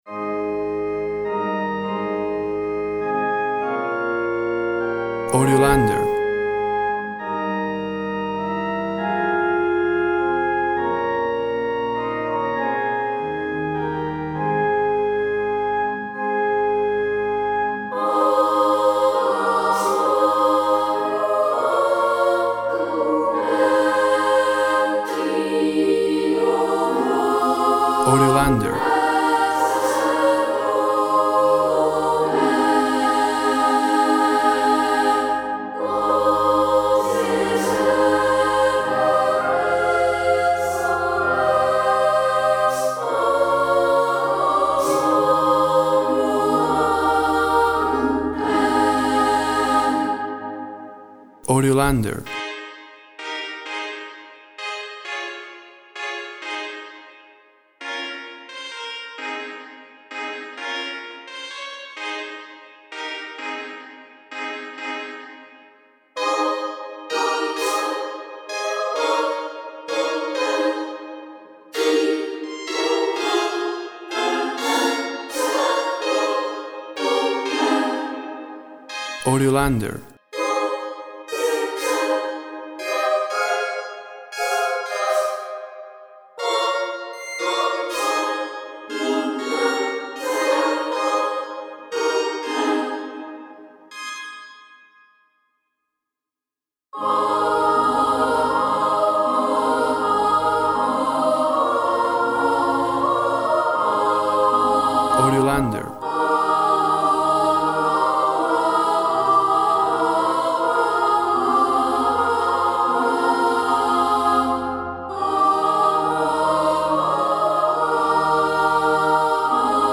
Tempo (BPM) 100/90